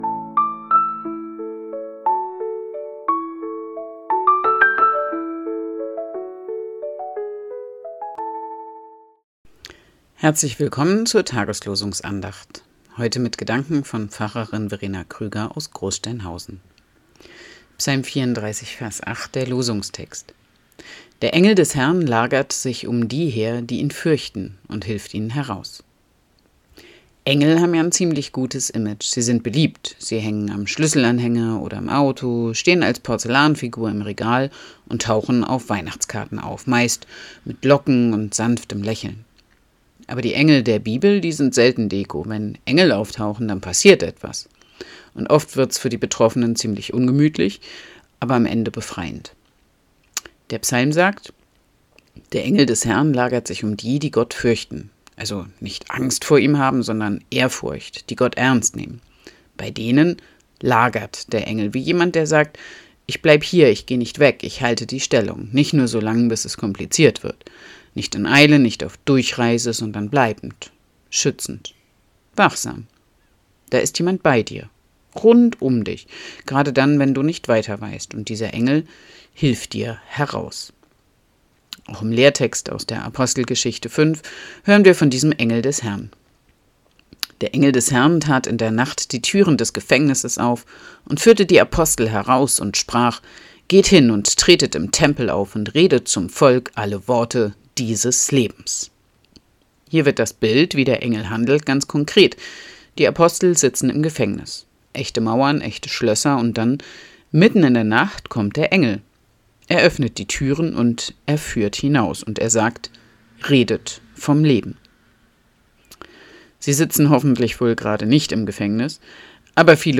Losungsandacht für Mittwoch, 14.01.2026
Losungsandachten